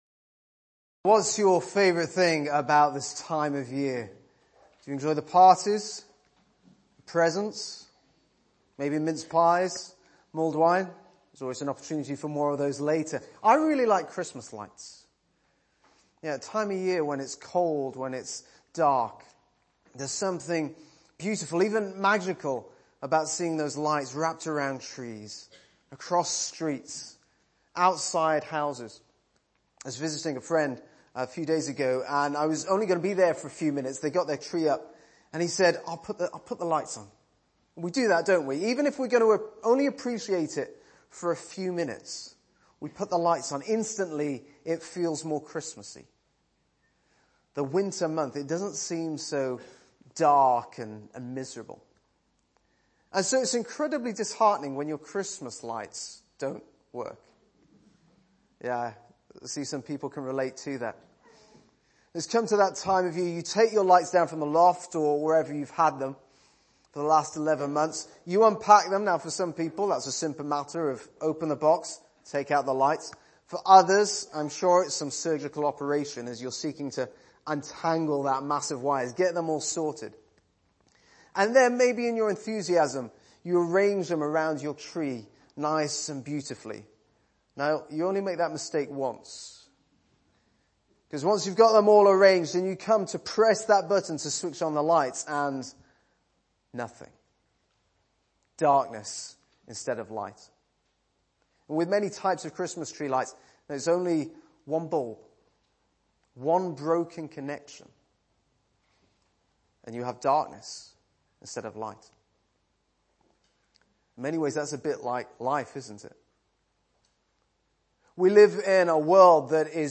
Topic: Carol Service, Christmas